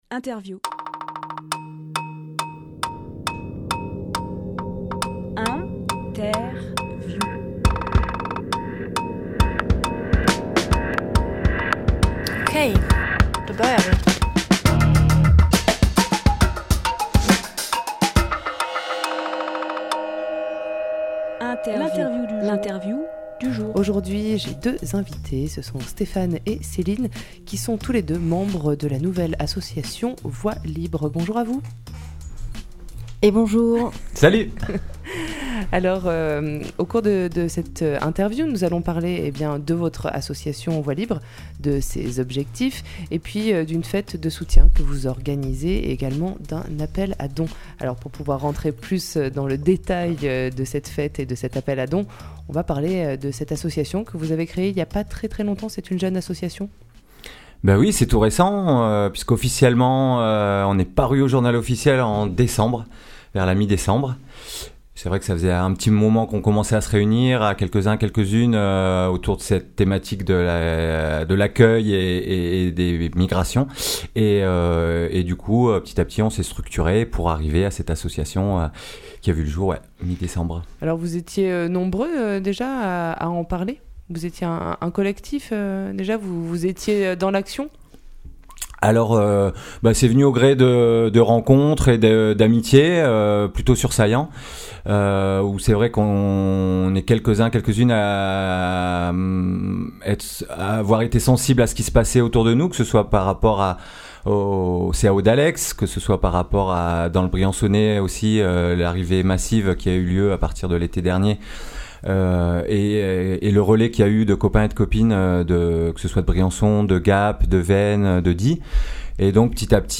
Emission - Interview Fête de soutien aux migrant(e)s avec Voies libres Publié le 23 mars 2018 Partager sur…